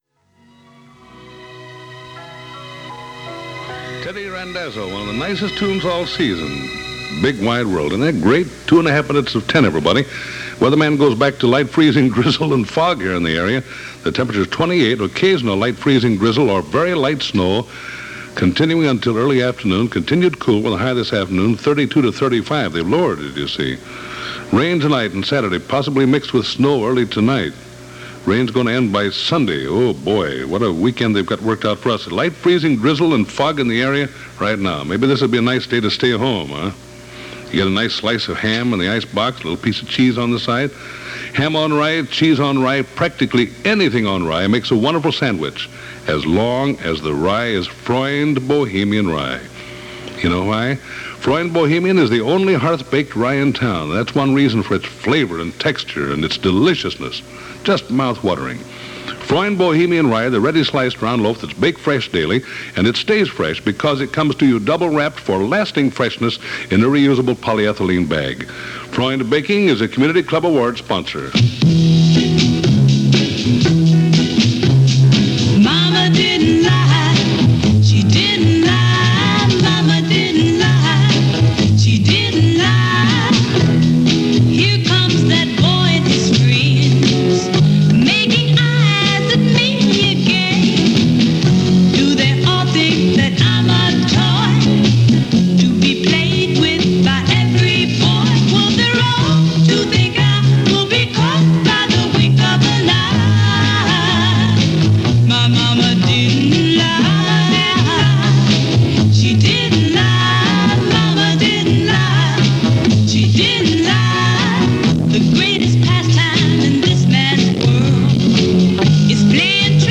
It's 1963 - You're A Teenager - You Live In St. Louis - You Keep Thinking About The Future - Past Daily Weekend Pop Chronicles.
WIL-Danny-Dark-January-4-1963.mp3